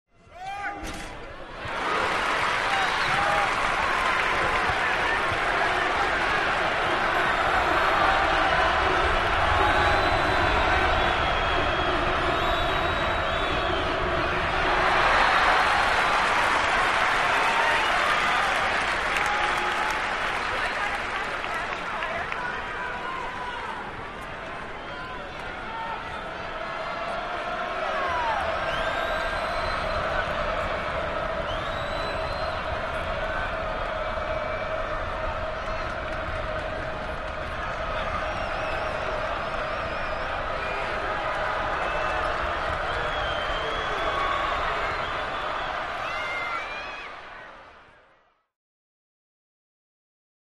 Basketball Crowd Cheers Free-throw Score And Gets Rowdy With One Big Swell